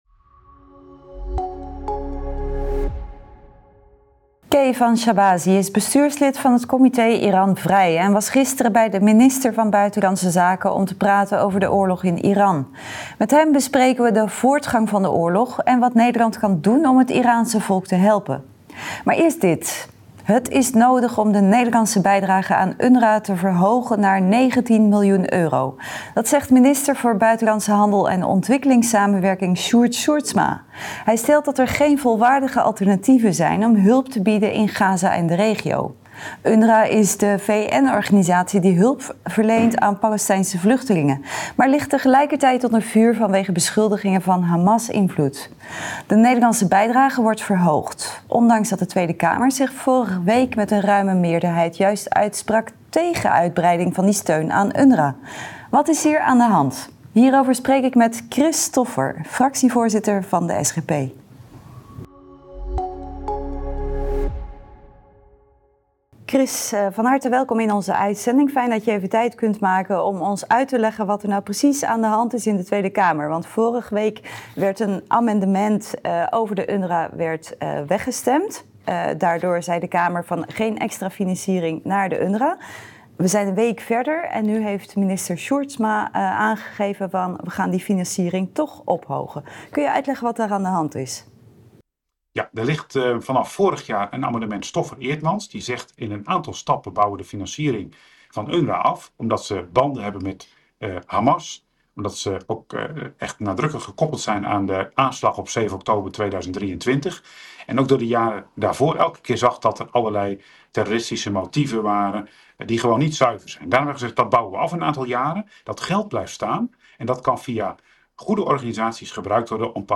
Dat bespreken we met Chris Stoffer, fractievoorzitter van de SGP.